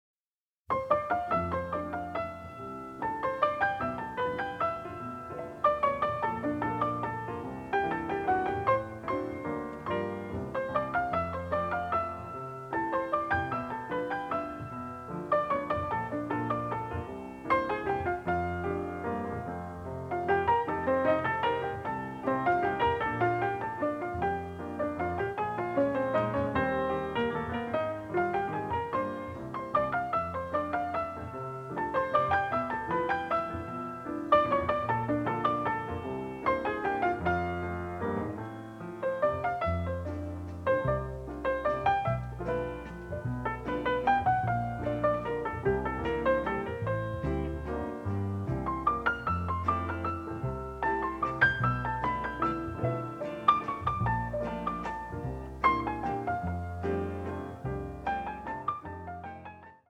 a colorful, bittersweet score
The score also features a bossa, a waltz and a tango.